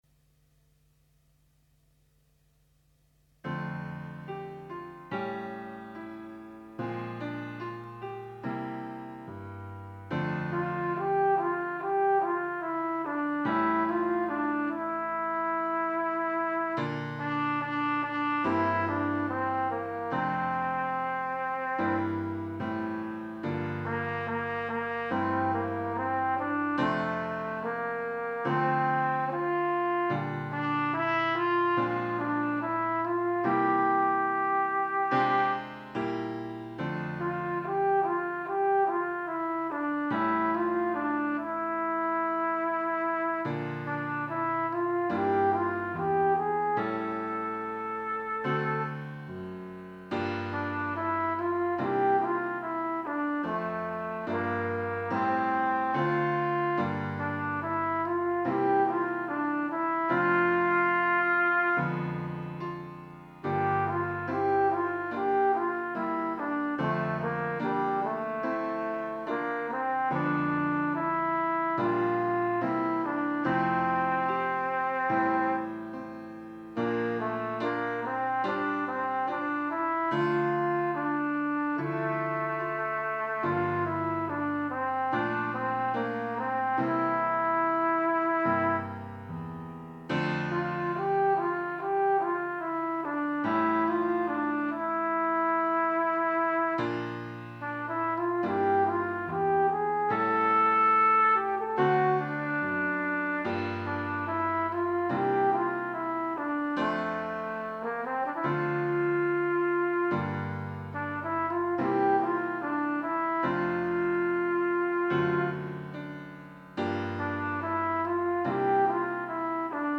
Instrumentation: C, Bb, Eb, Keyboard
An easy melodic piece with a gentle jazz feel.
Arranged for Solo instrument with keyboard.